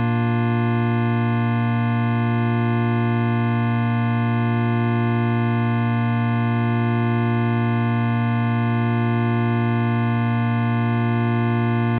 bb-chord.ogg